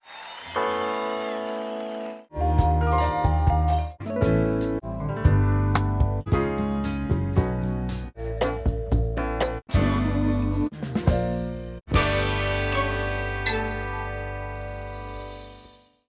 Mu major example: sound collage
(stereo, 16 bits, 22kHz)